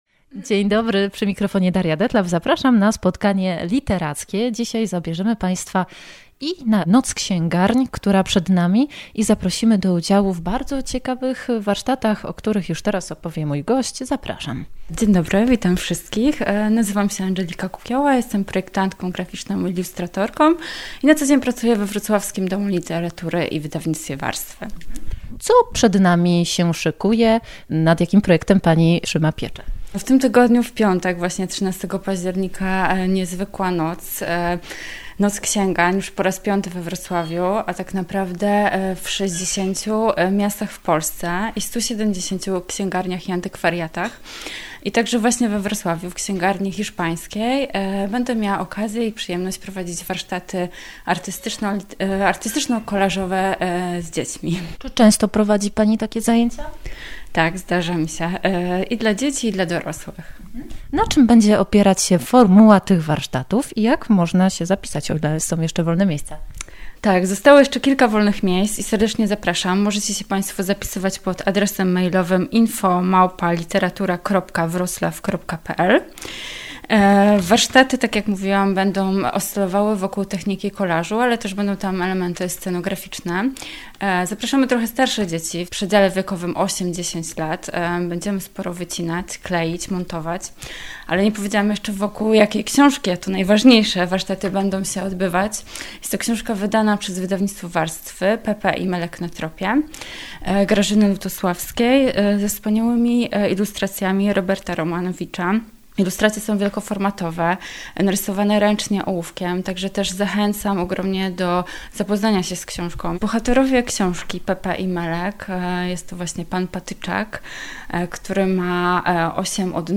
Naszym gościem jest graficzka, ilustratorka